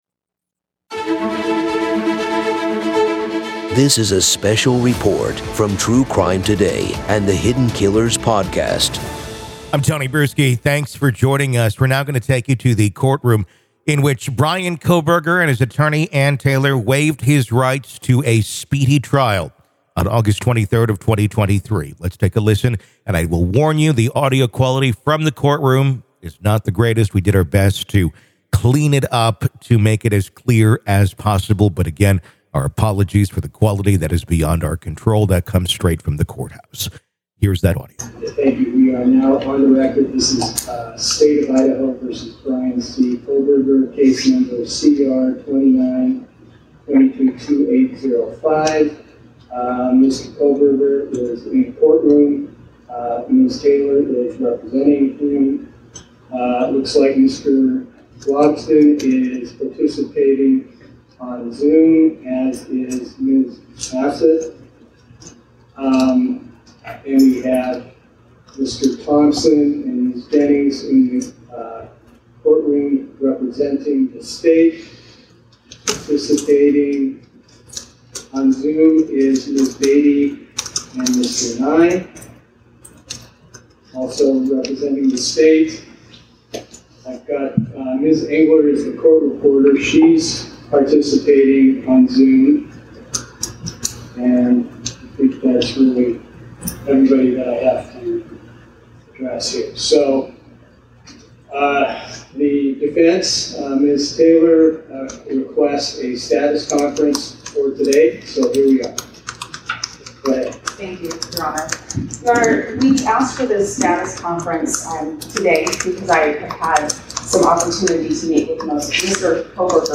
Court Audio Bryan Kohberger Waives His Right To Speedy Trial